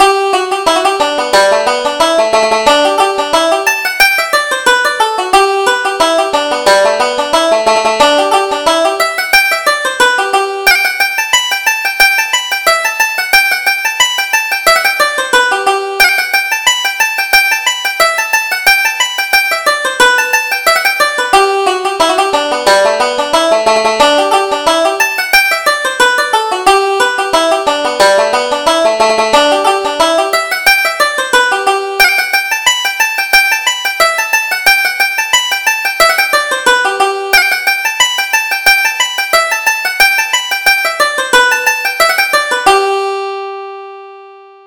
Reel: McFadden's Favorite